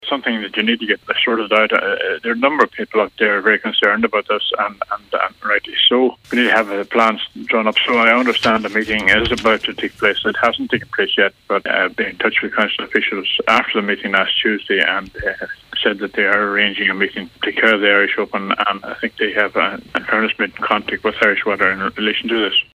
With thousands expected to descend on the village in July, Local Cllr Bernard McGuinness says the issue must be addressed as soon as possible: